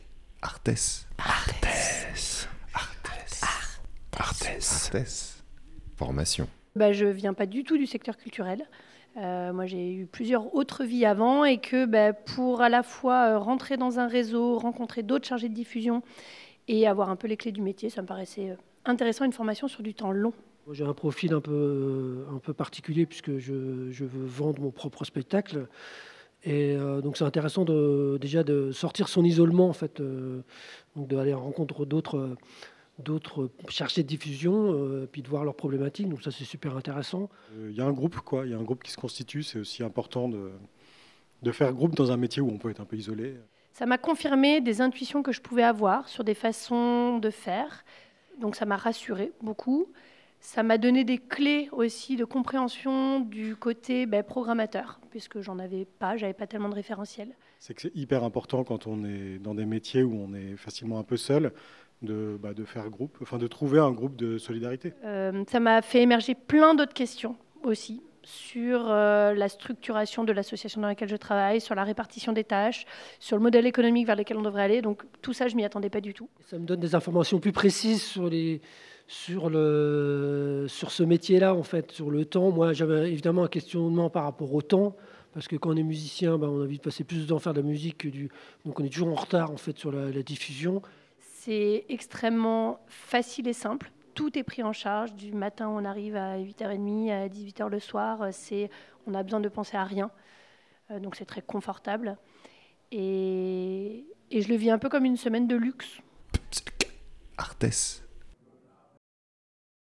Pour fêter les 15 ans du cycle "Réussir sa mission de chargé.e. de diffusion", en fin d'année dernière, nous avons pris le temps d'enregistrer plusieurs participants avec un bon micro (merci aux intervenants de la formation podcast...).